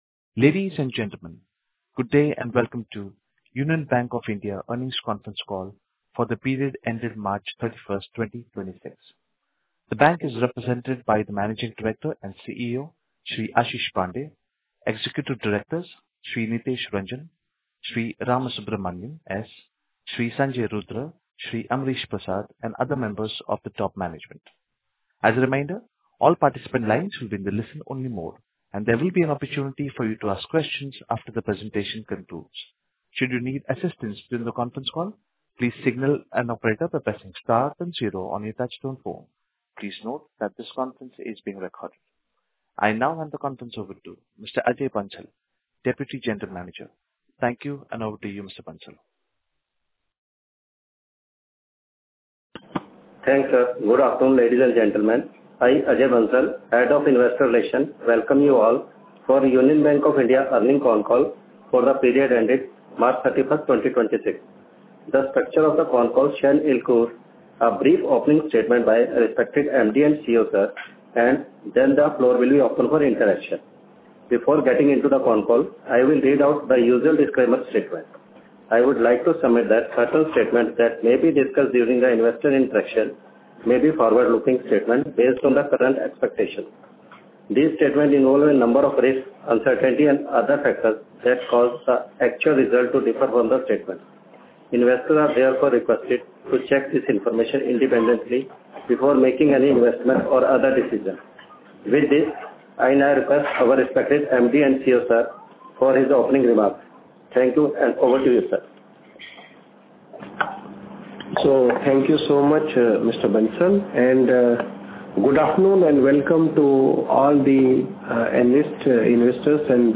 Conference Call Overview
Following the successful conclusion of the Q4 (January-March) and annual financial review, Union Bank of India hosted an earnings call to discuss its performance. The session provided management’s perspective on the bank’s operational achievements, financial health, and strategic direction for the fiscal year ending March 31, 2026.